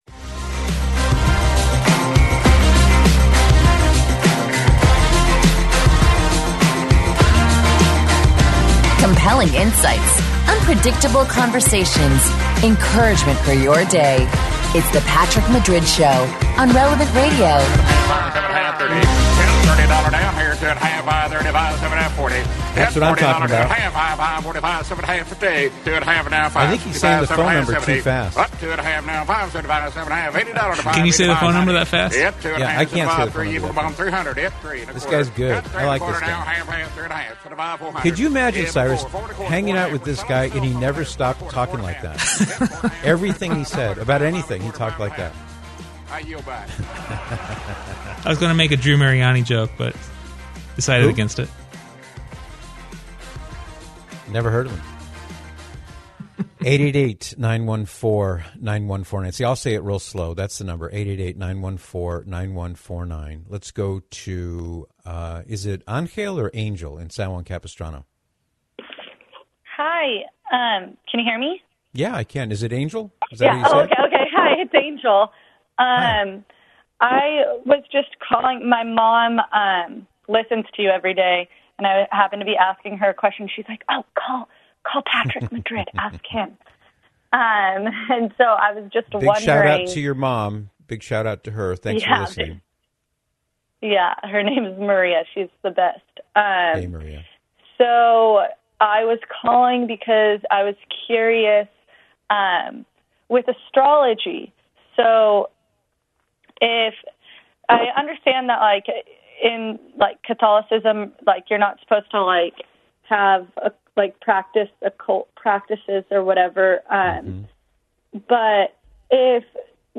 In other words, she was asking if there was any way one could reconcile the beliefs of astrology with the beliefs of the Catholic Church.